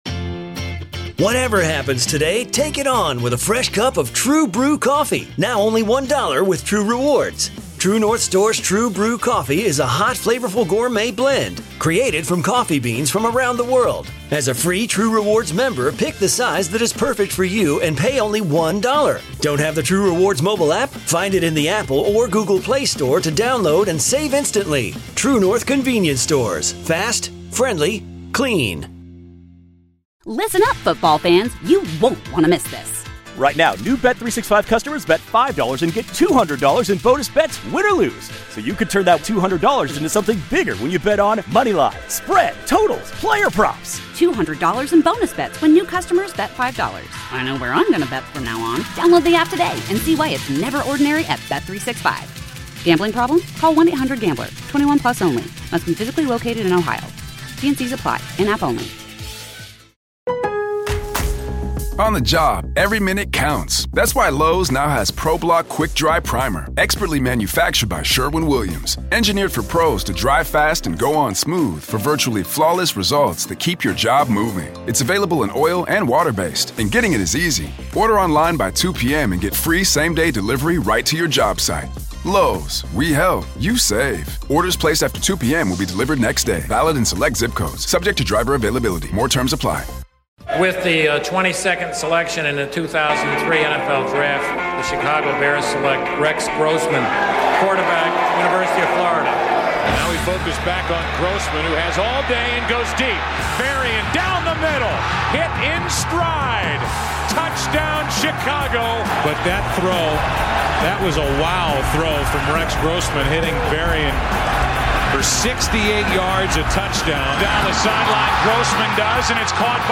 Interviews of the Week on 670 The Score: Nov. 10-14